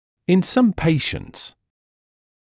[ɪn sʌm ‘peɪʃnts][ин сам ‘пэйшнтс]у некоторых пациентов